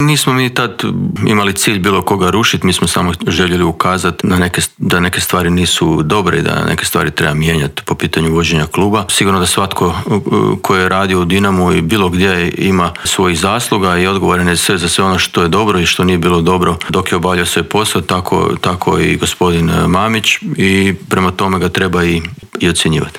Šefa struke Dinama ugostili smo u Intervjuu tjedna Media servisa.